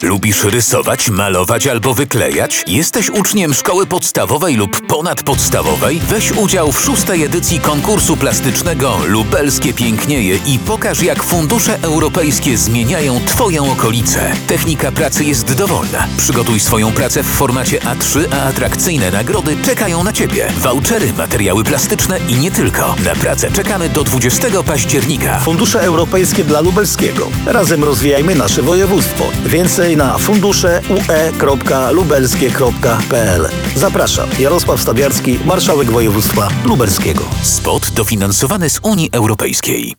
Audycja radiowa 180 sek. dot. Działania 3.4 Zrównoważona gospodarka wodno-ściekowa